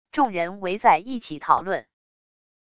众人围在一起讨论wav音频